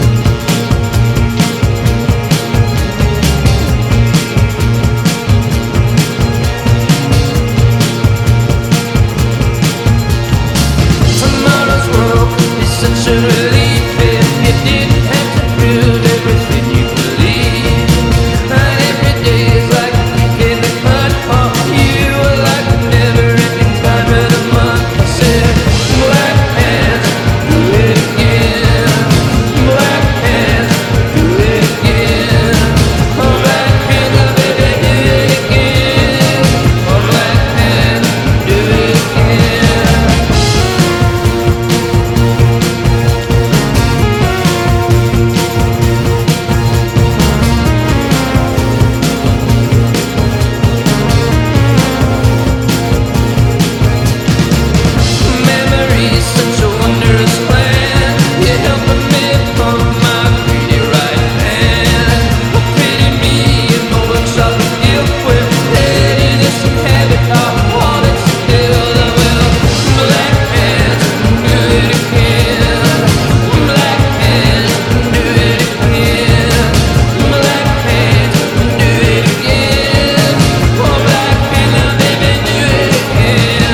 ROCK / 90''S～ (UK)